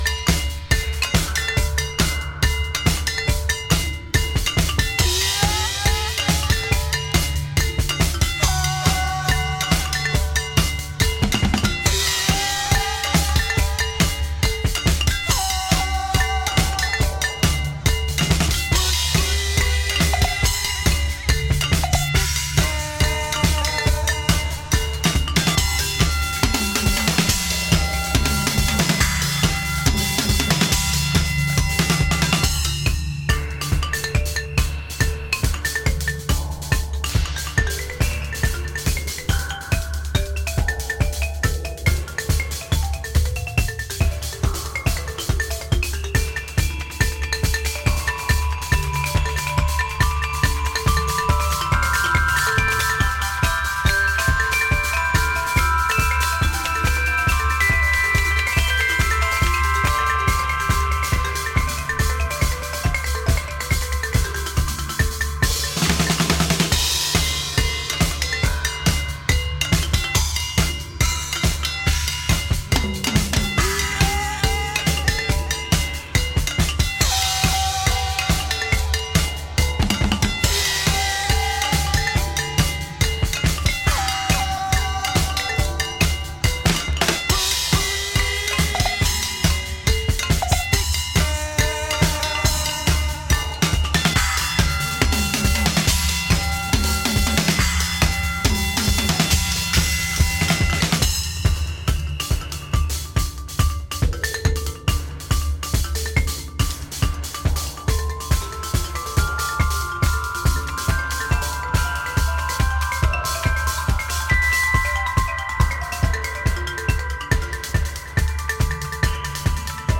マリンバ、ドラム、ベルといった鳴り物で空間が満たされるダイナミックな仕上がりが最高です。